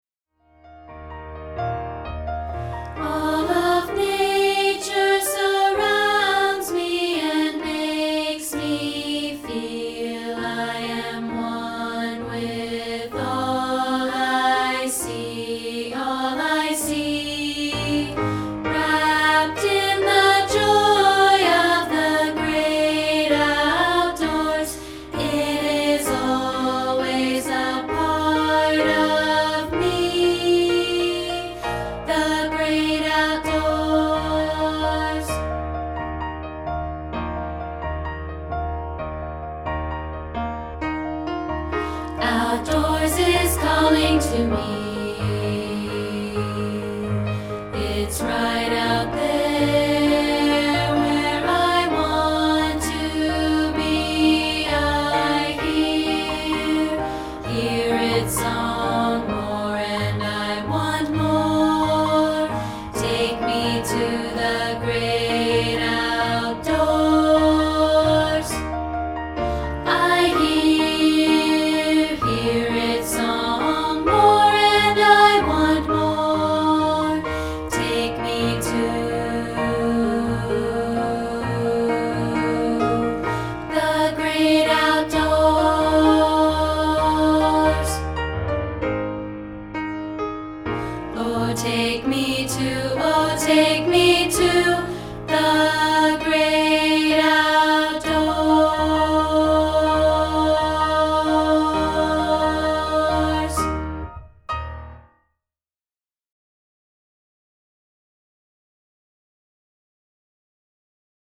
This is the rehearsal track of part 2, isolated.